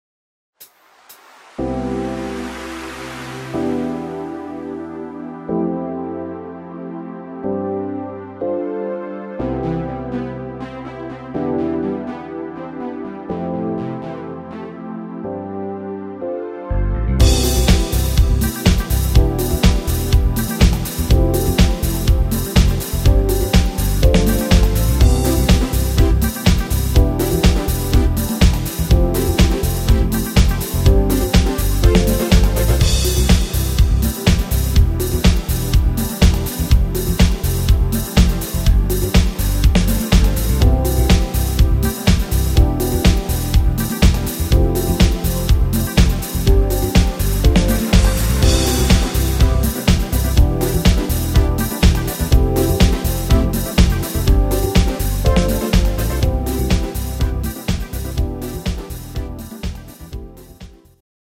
Dance version